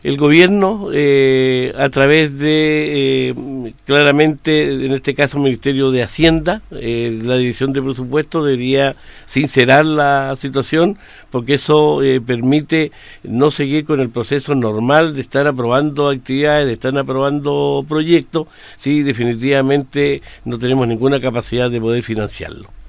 Así lo reconoció en entrevista con Radio Bío Bío, el gobernador Luis Cuvertino, revelando que nuevamente fueron víctimas de un “manotazo” por parte del Ministerio de Hacienda, que otra vez recortó el presupuesto destinado a la billetera regional.